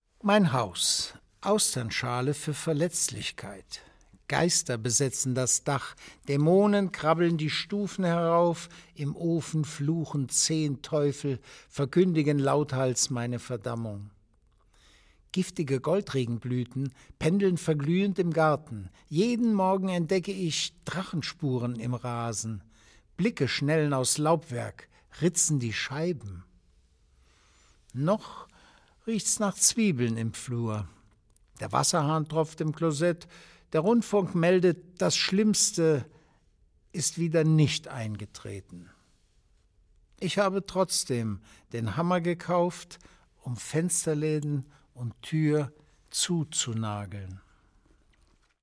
Eine Auswahl an Gedichten von Heinrich Kraus, gelesen